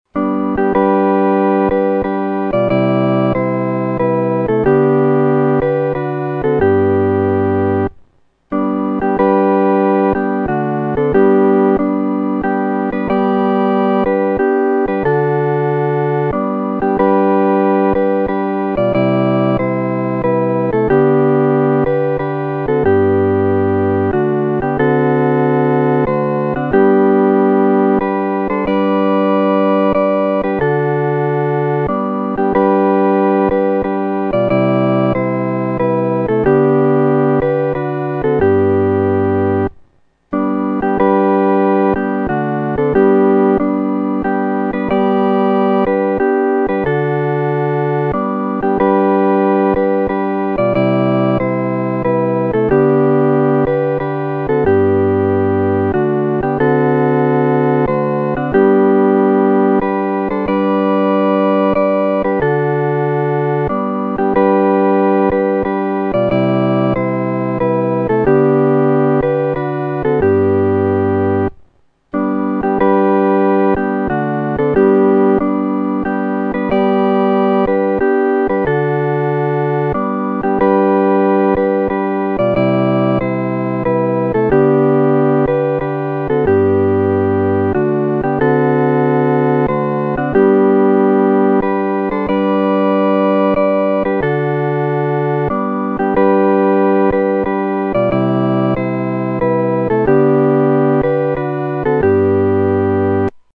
合奏（四声部）
靠近主-合奏（四声部）.mp3